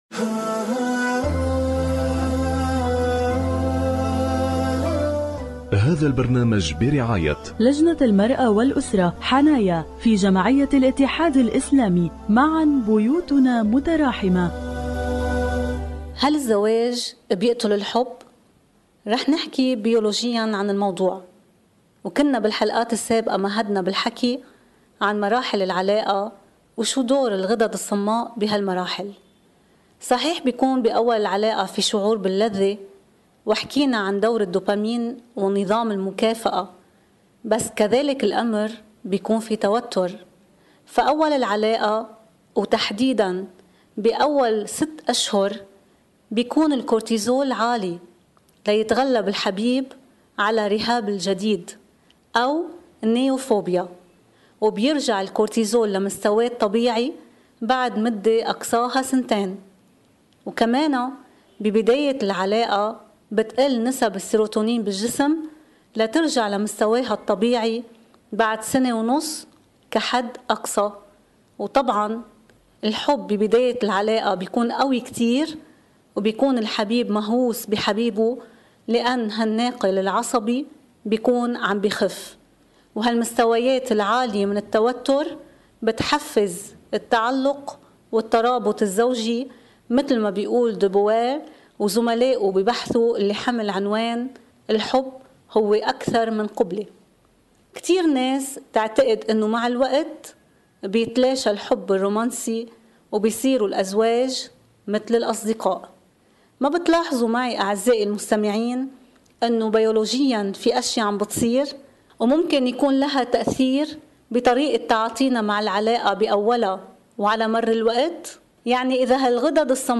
برعاية لجنة المرأة والأسرة-حنايا في جمعية الاتحاد الإسلامي على إذاعة الفجر.